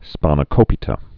(spänə-kōpē-tə, spănə-)